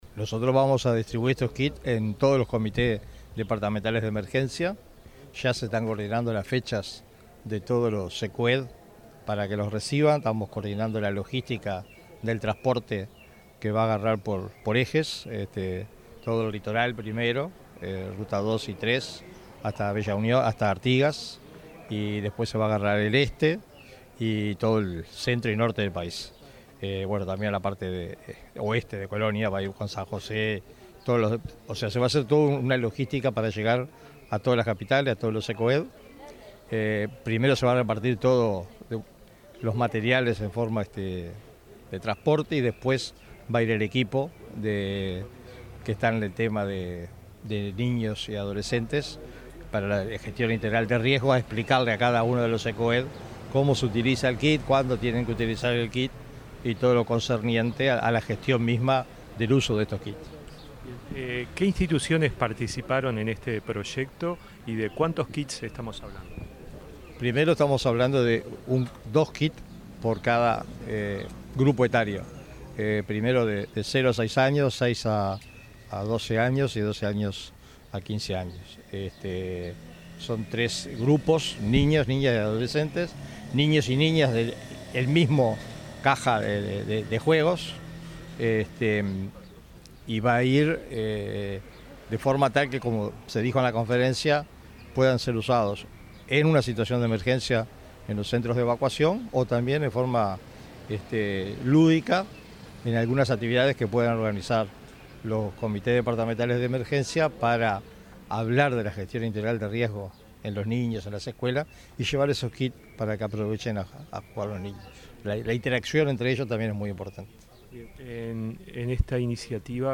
Declaraciones del director del Sinae, Sergio Rico
Este jueves 17 en el Centro Coordinador de Emergencias Departamentales (Cecoed) de Montevideo, el Sistema Nacional de Emergencias (Sinae) y Unicef entregaron el primer kit de materiales didácticos para la recreación educativa en situaciones de emergencia con niños y adolescentes. El director del Sinae, Sergio Rico, dialogó luego con la prensa.